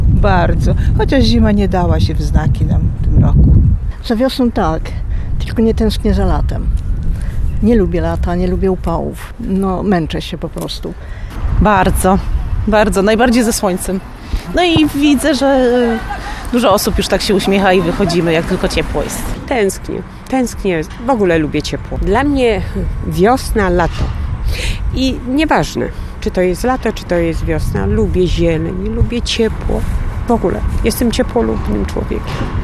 Zapytaliśmy przechodniów w Suwałkach, czy tęsknią za tym okresem. Większość z nich chętnie powitałaby wiosnę – tęsknią za jej ciepłem, zielonymi krajobrazami i pierwszymi promieniami słońca.